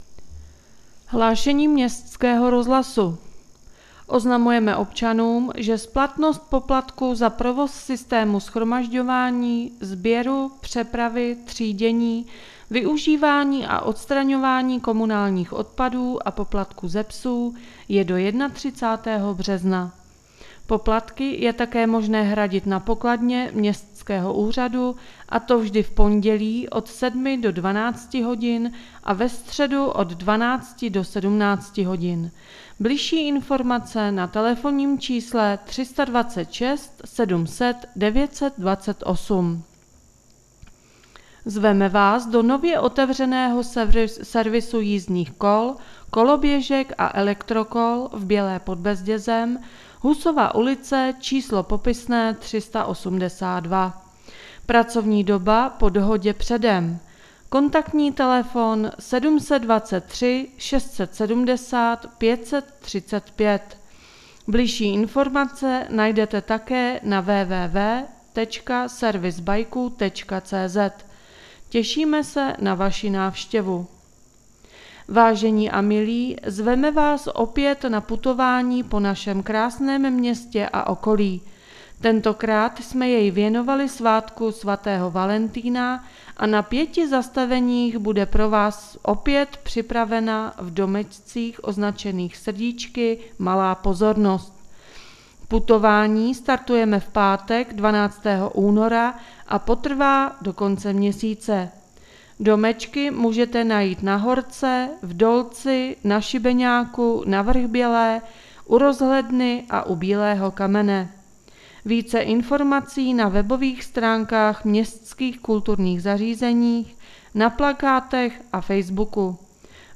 Hlášení městského rozhlasu 8.2.2021